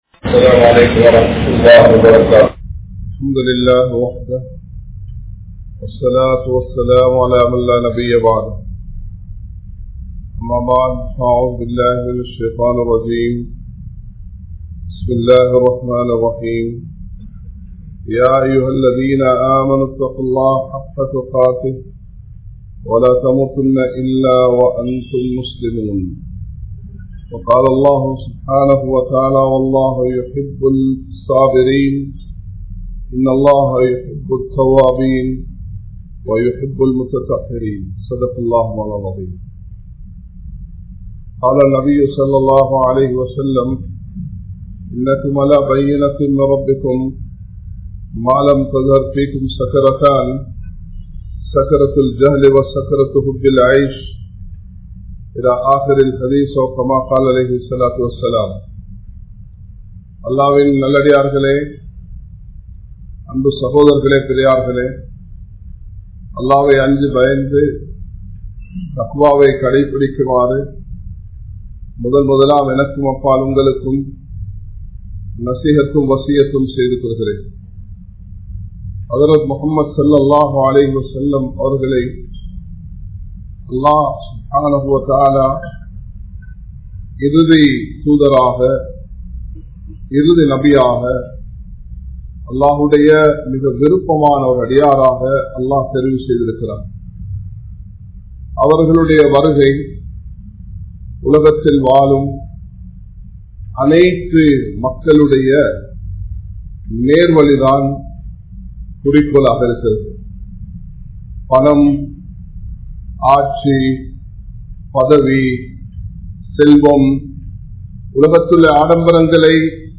Manithanin Iruthi Irupidam Kabur (மனிதனின் இறுதி இருப்பிடம் கப்ர்) | Audio Bayans | All Ceylon Muslim Youth Community | Addalaichenai
Colombo 04, Majma Ul Khairah Jumua Masjith (Nimal Road)